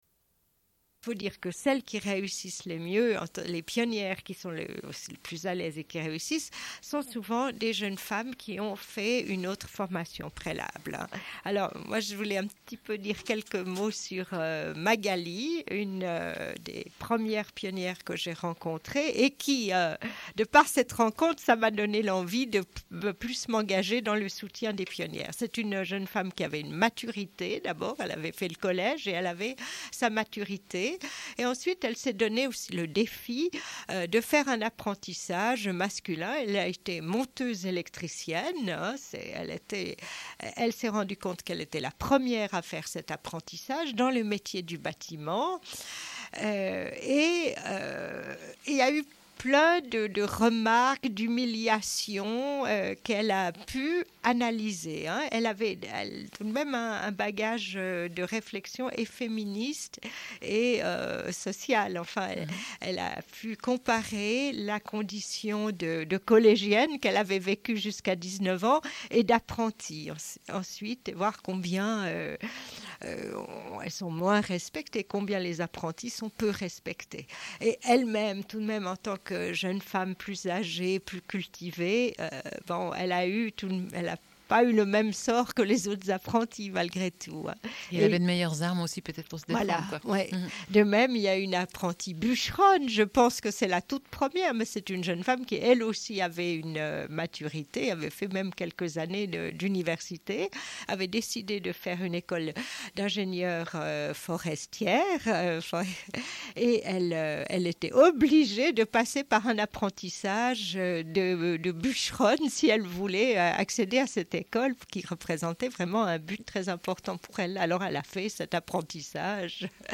Une cassette audio, face B
Radio